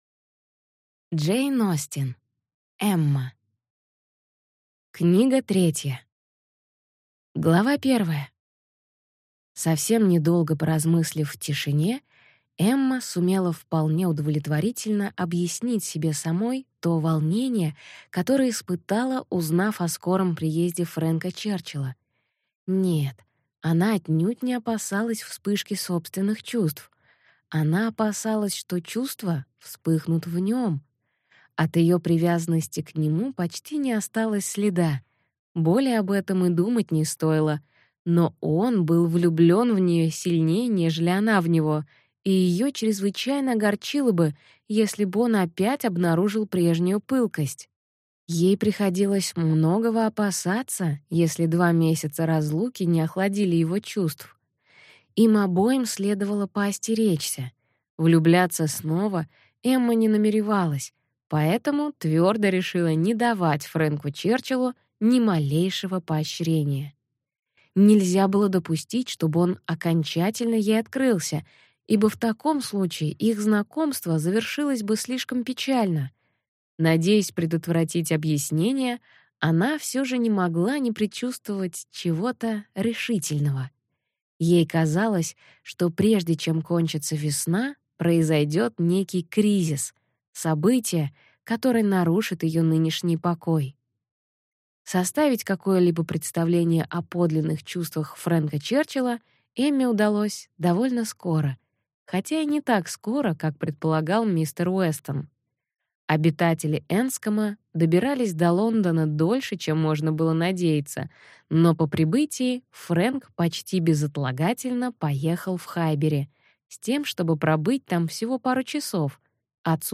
Аудиокнига Эмма. Книга 3 | Библиотека аудиокниг